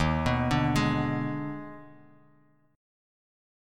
D#M7sus4#5 chord